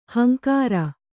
♪ hankāra